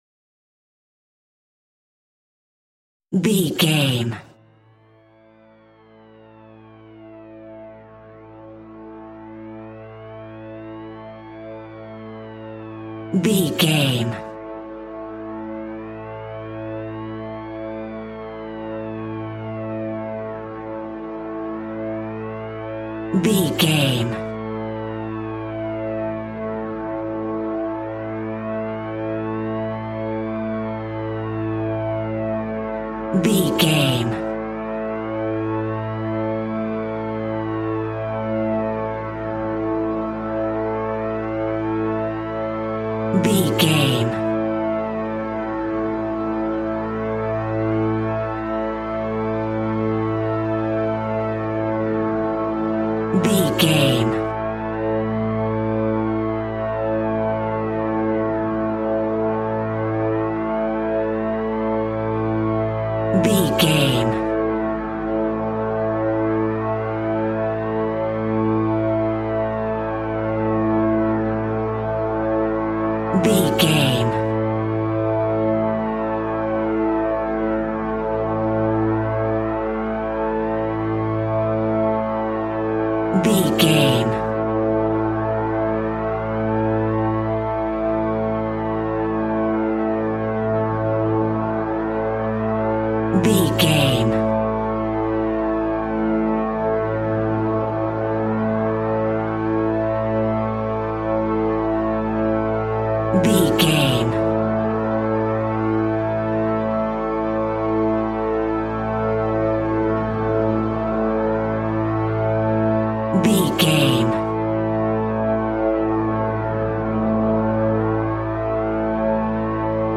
In-crescendo
Thriller
Aeolian/Minor
Slow
scary
tension
ominous
dark
haunting
eerie
synthesiser
horror
Synth Pads
atmospheres